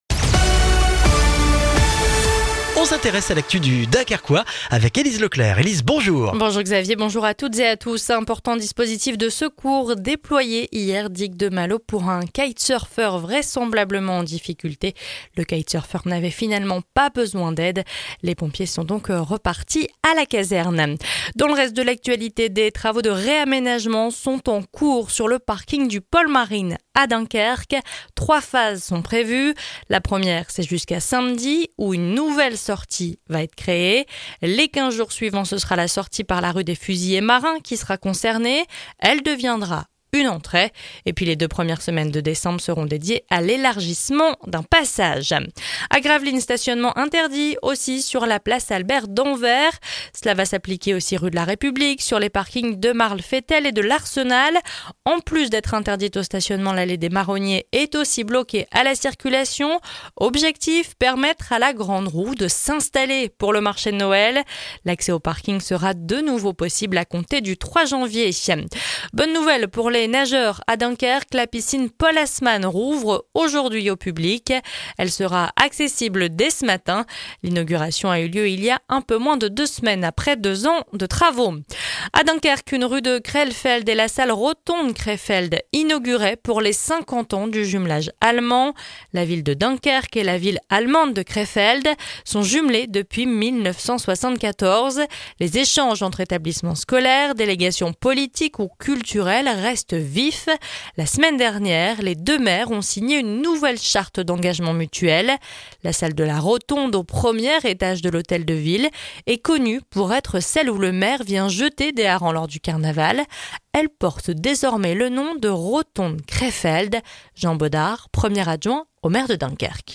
Le journal du mardi 12 novembre dans le Dunkerquois